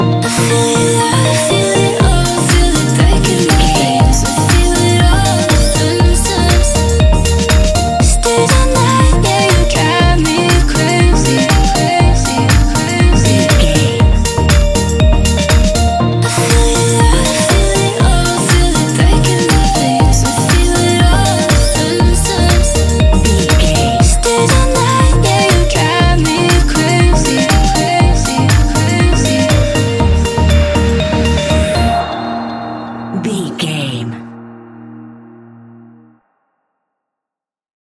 Ionian/Major
D♯
house
electro dance
synths
trance